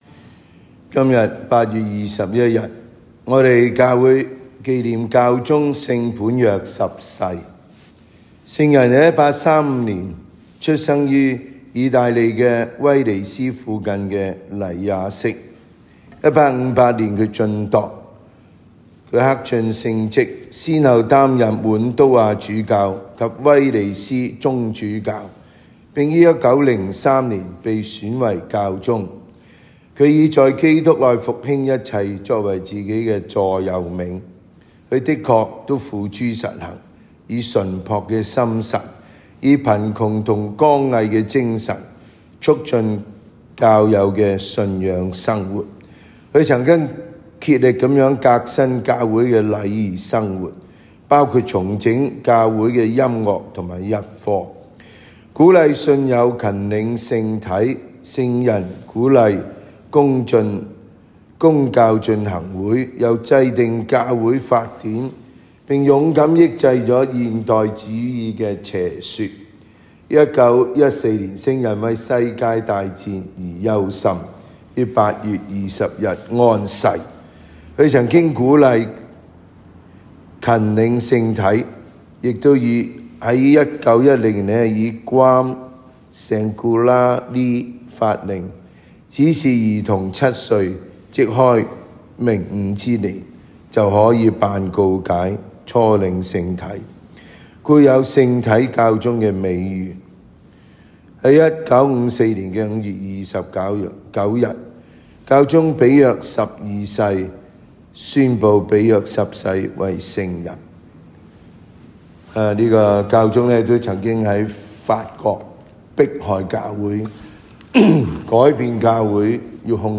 每日講道及靈修講座